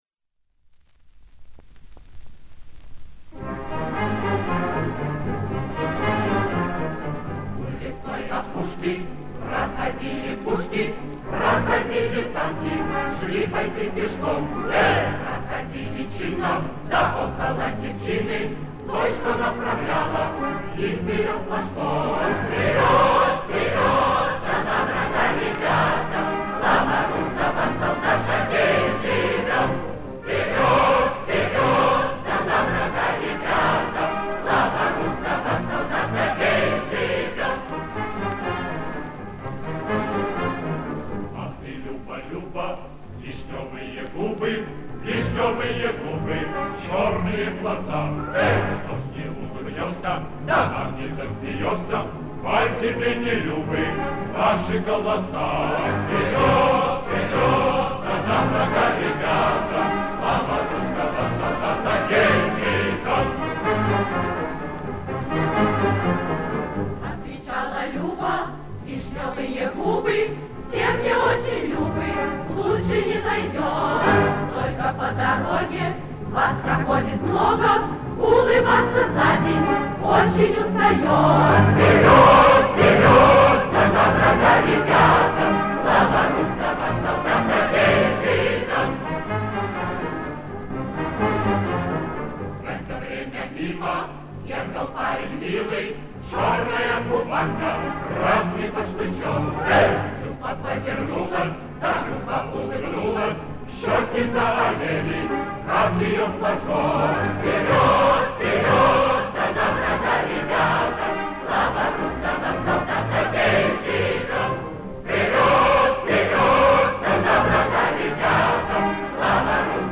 Солнечная, задорная песня о девушке-регулировщице.